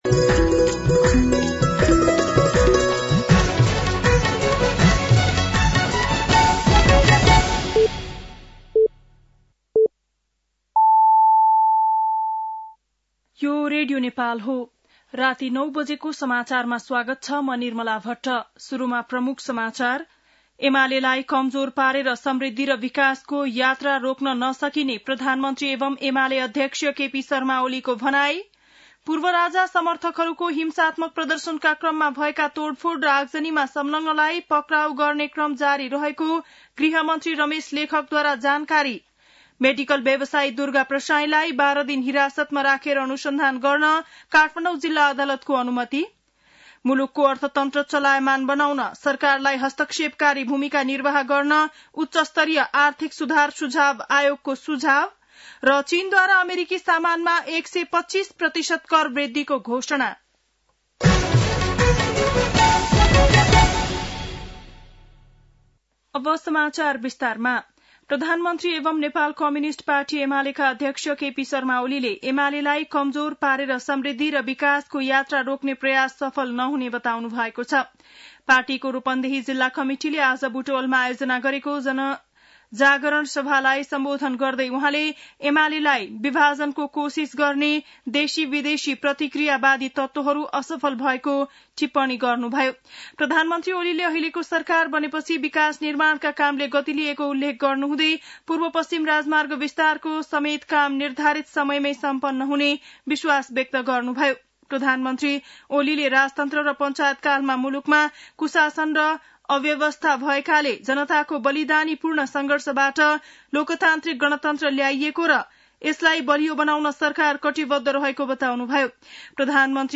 बेलुकी ९ बजेको नेपाली समाचार : २९ चैत , २०८१
9-PM-Nepali-NEWS-12-29.mp3